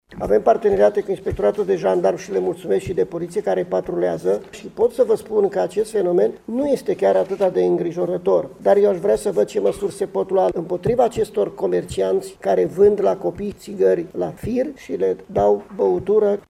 116 școli din Mureș s-au arătat interesate de informațiile de prevenire. În două luni, specialiștii au avut contact cu peste 6.000 de adolescenți. Inspectorul Școlar General, Ioan Macarie: